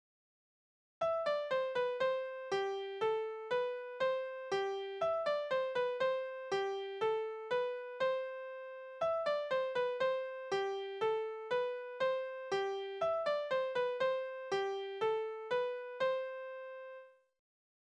Tanzverse: Katze und Maus Tanz
Tonart: C-Dur
Taktart: 4/4
Tonumfang: große Sexte
Besetzung: vokal